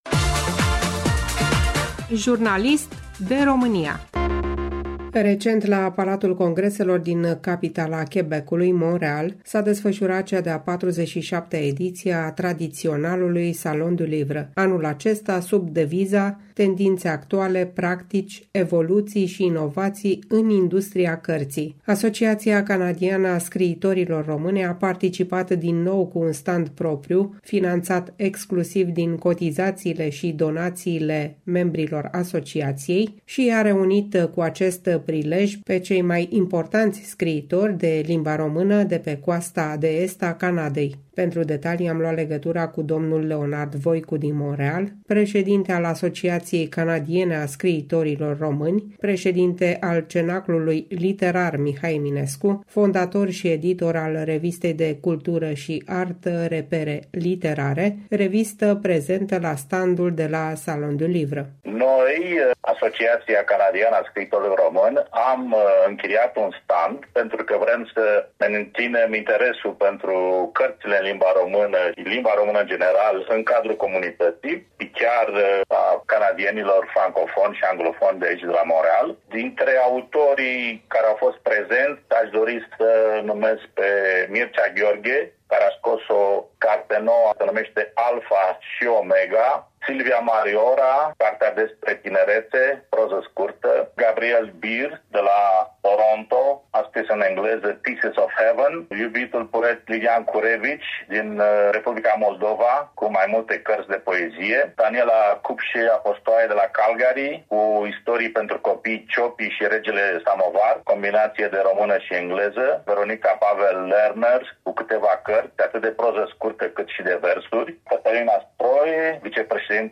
Revista Repere literare din Canada prezentată la cea de-a 47-a ediție a tradiționalului Salon du Livre din capitala Quebecului, Montreal. Interviu